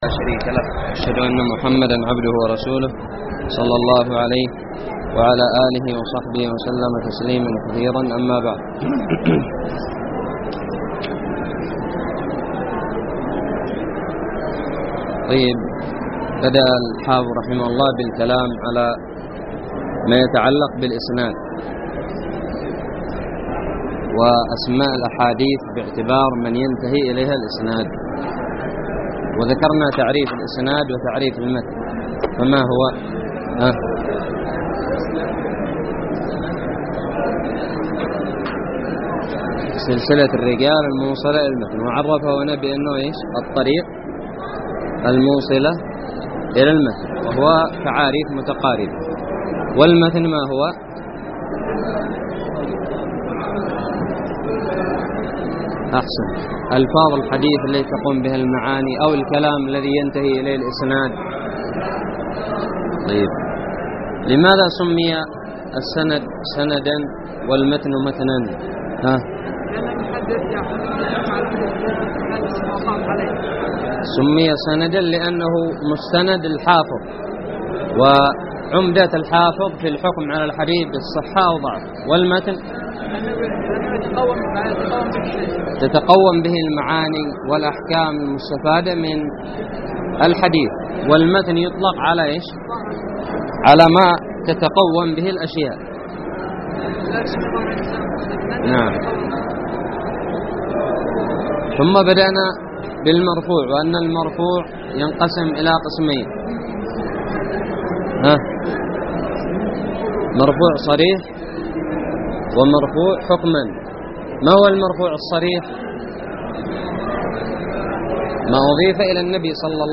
الدرس الخامس والثلاثون من شرح كتاب نزهة النظر
ألقيت بدار الحديث السلفية للعلوم الشرعية بالضالع